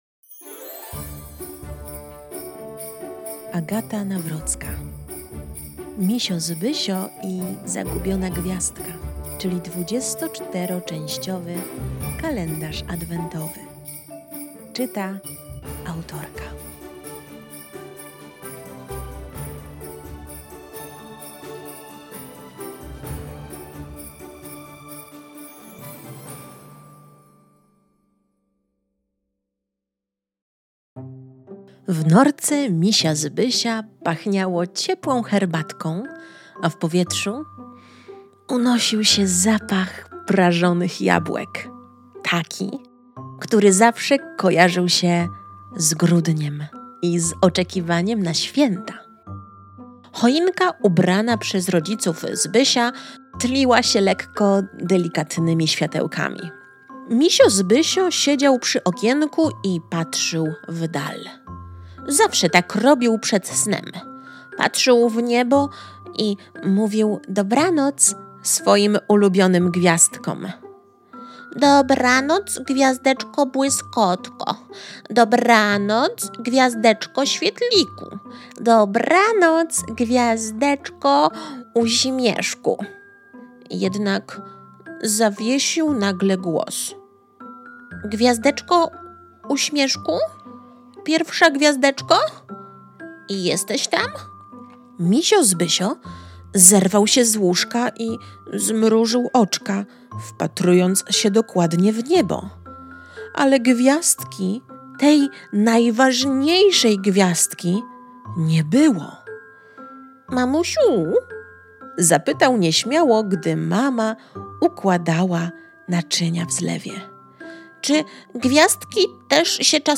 To nie są zwykłe bajki. To jedna, długa superprodukcja audio, podzielona na 24 odcinki – niczym serial adwentowy dla dzieci.
• profesjonalne słuchowisko, podzielone na 24 rozdziały
• piękne tła dźwiekowe, ułatwiające wyciszenie
Mają delikatną nutę tajemnicy, ale są w 100% bezpieczne emocjonalnie.